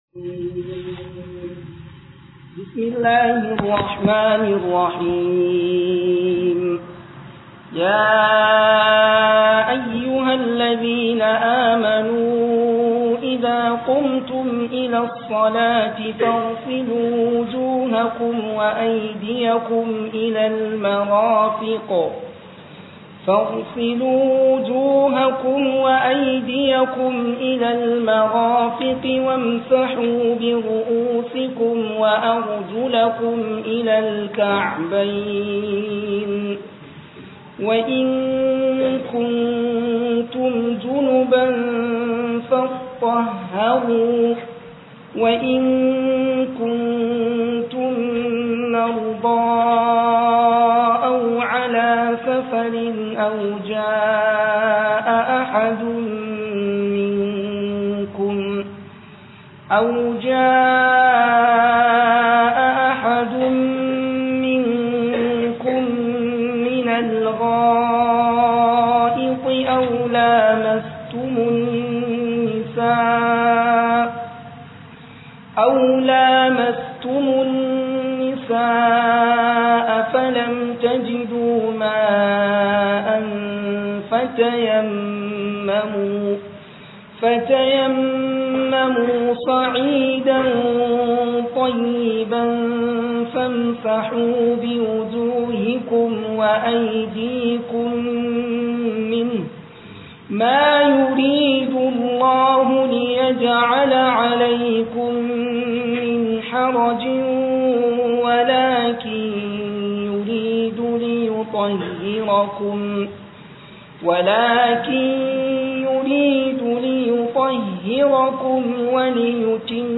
022 RAMADAN TAFSIR